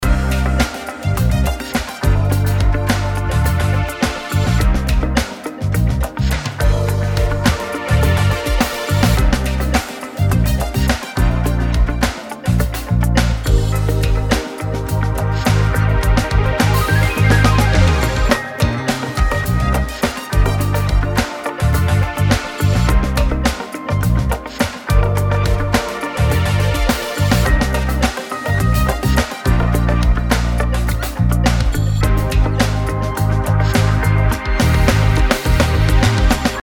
Подрулил дефолтный пресет и чуть верх у тарелок окрасил.
Согласен, химия всё равно есть, но сейчас хоть тарелки рассыпаются, имхо.